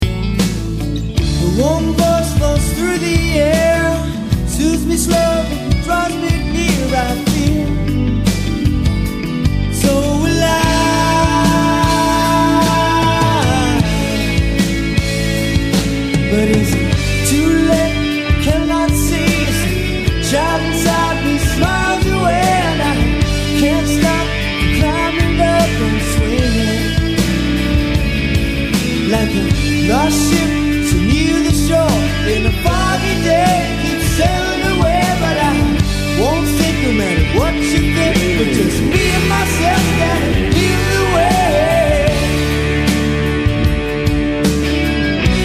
to the more melodic